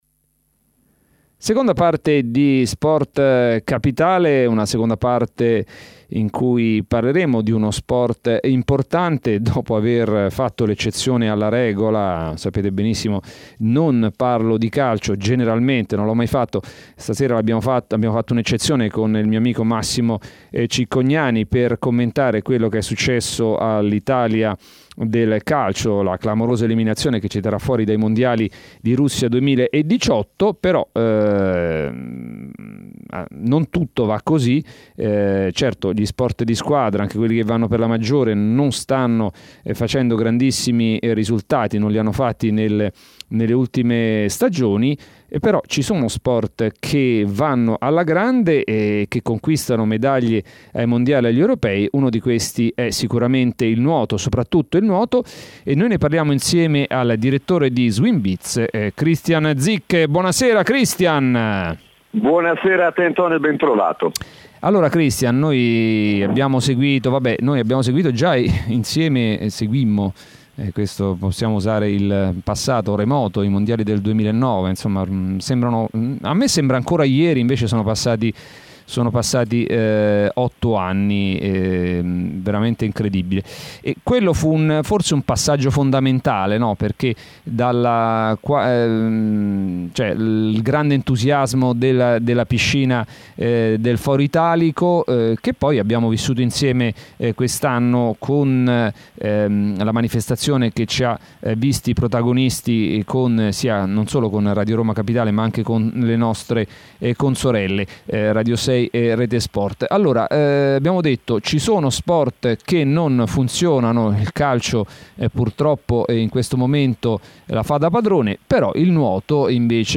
trasmissione di Radio Roma Capitale